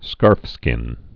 (skärfskĭn)